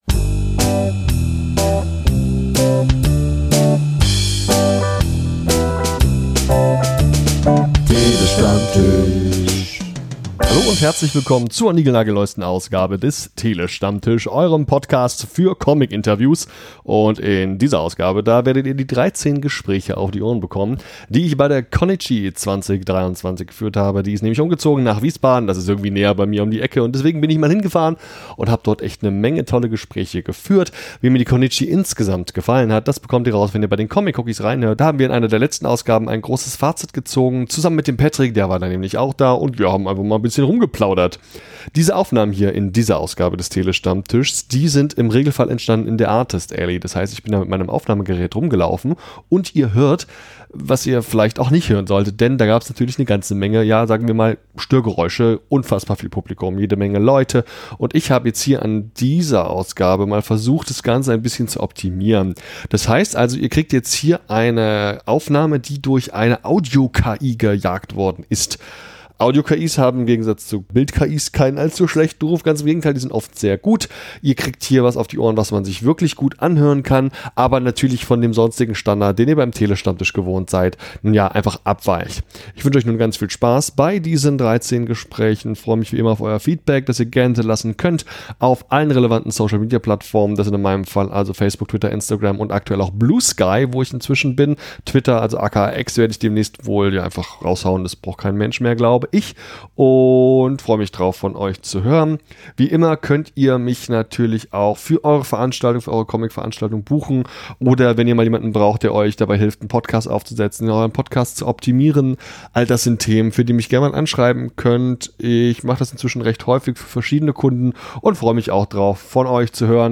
TST090 - Interviews auf der Connichi 2023 ~ Der Tele-Stammtisch - Comictalks & Interviews Podcast
Ich hatte nicht nur viel Spaß dort, ich habe außerdem ein paar tolle Interviews geführt.